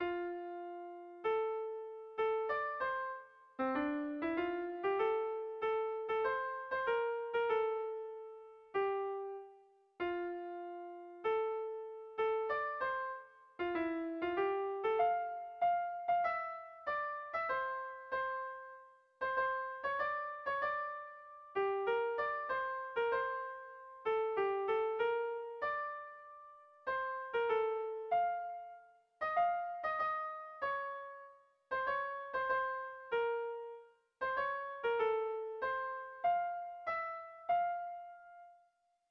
Erlijiozkoa
ABDE